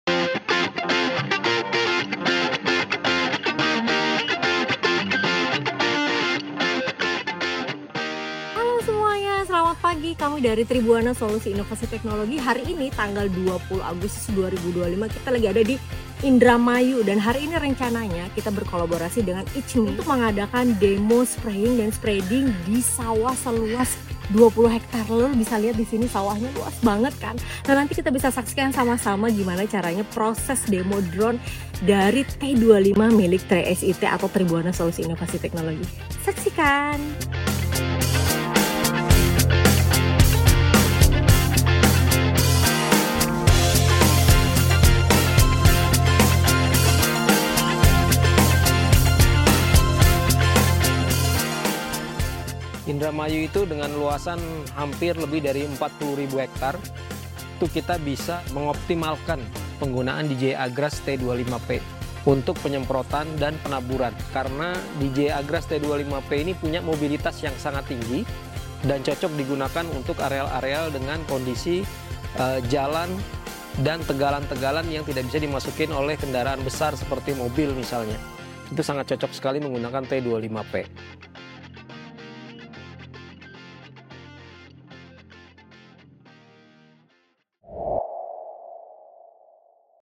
DEMO DJI AGRAS T25P SPRAYING & SPREADING PADI 📍 Desa Wanasari, Kabupaten Indramayu